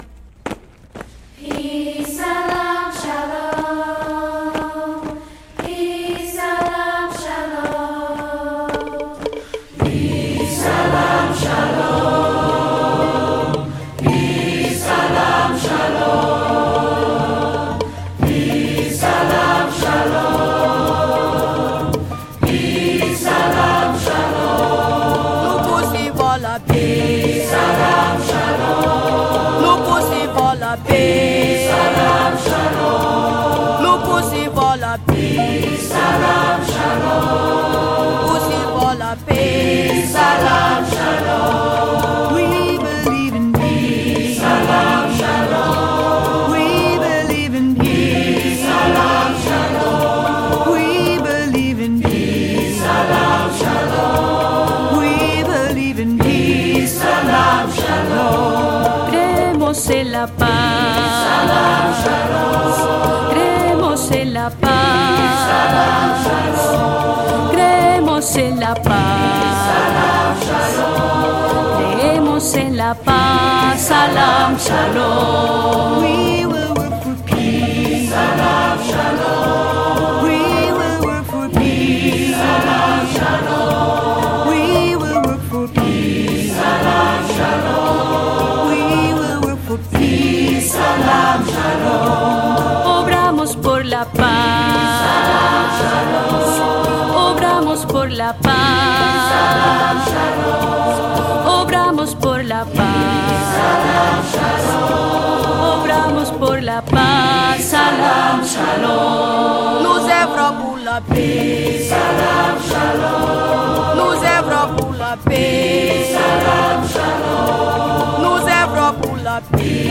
a chant worth repeating